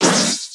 stunned_1.ogg